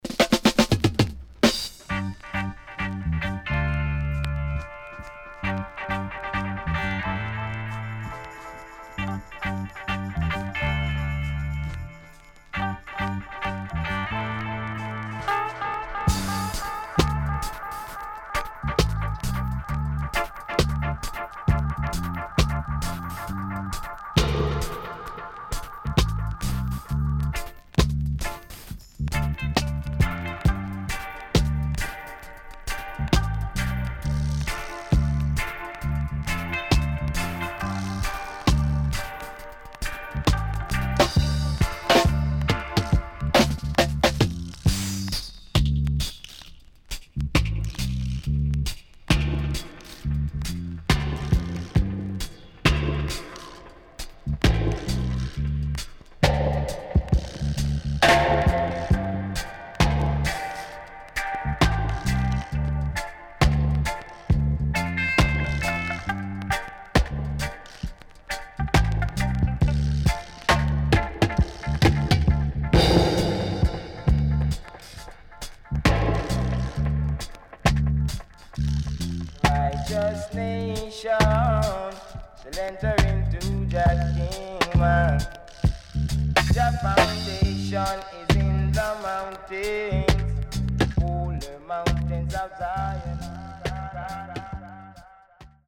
CONDITION SIDE A:VG+〜EX-
SIDE A:少しチリノイズ入りますが良好です。